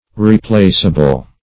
Replaceable \Re*place"a*ble\ (r?-pl?s"?-b'l), a.